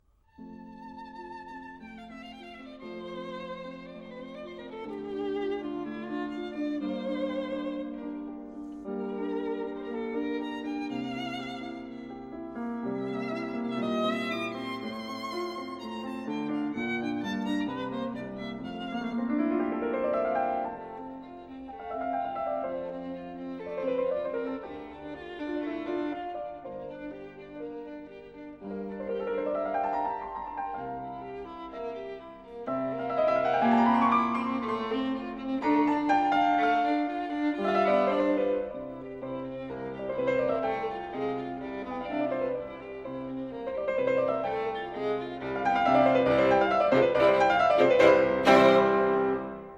As a closing let us listen to the exposition of Beethoven’s Violin Sonata no.5 (op.24.), commonly known as the Spring Sonata!